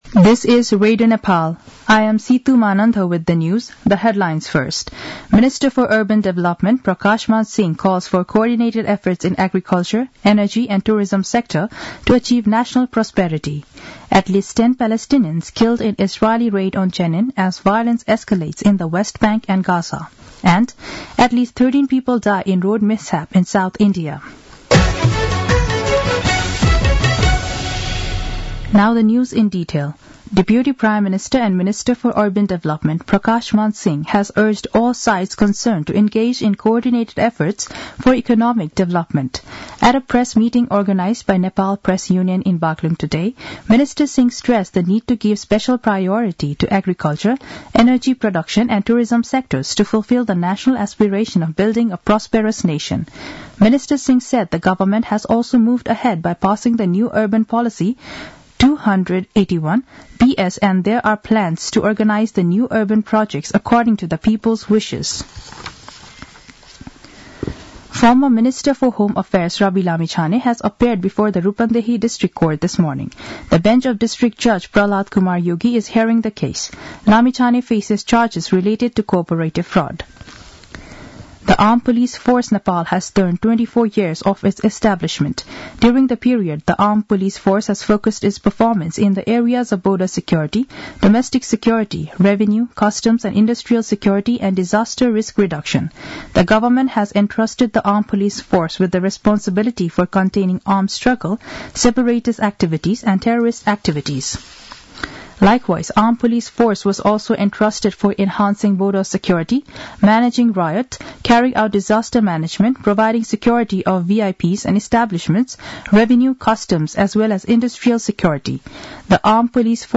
दिउँसो २ बजेको अङ्ग्रेजी समाचार : १० माघ , २०८१